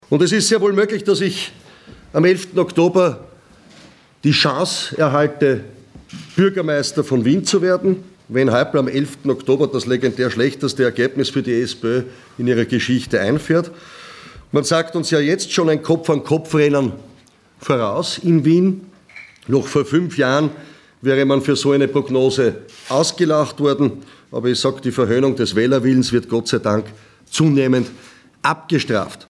O-Töne von HC Strache